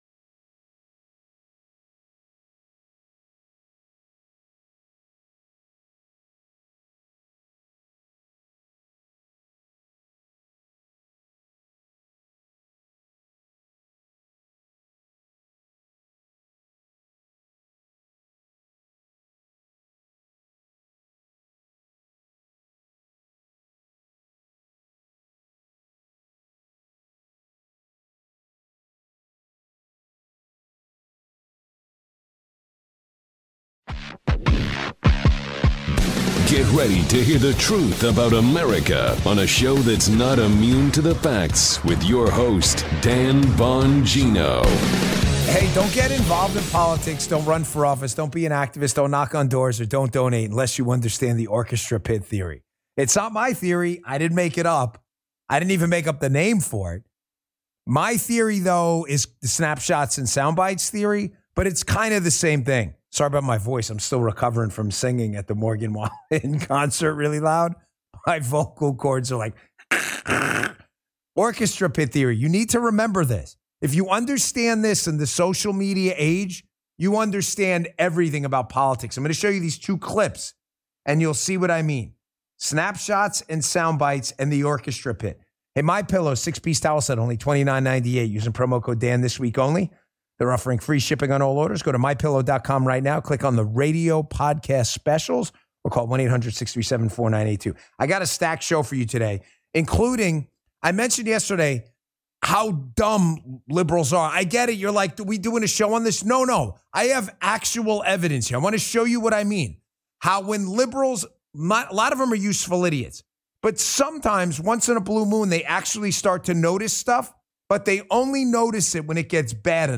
➡ The text revolves around the host, Dan Bongino's podcast where he discusses 'Orchestra Pit Theory and Snapshots' in politics and the manipulation by media. He also draws a global political shift towards right-wing populism, pointing to the recent election of Javier Milei in Argentina, drawing parallels with former President Donald Trump's political strategy.